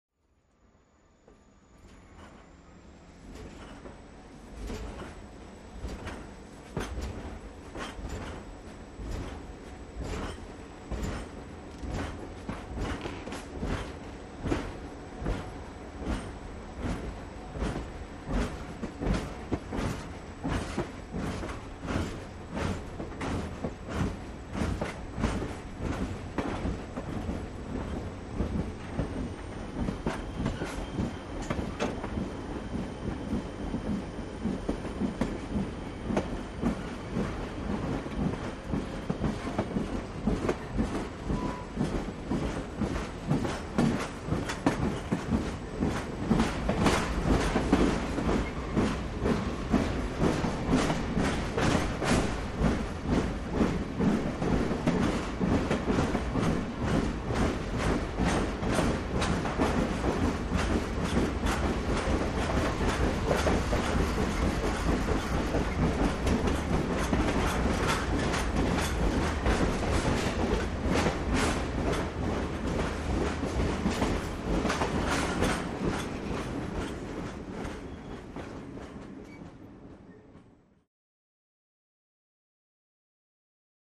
Start, Train | Sneak On The Lot
Train Starts Moving From Dead Stop To Steady